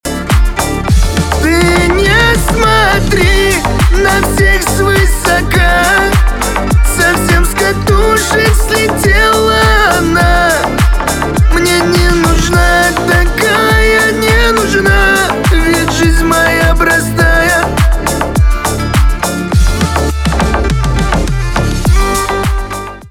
кавказские
битовые , басы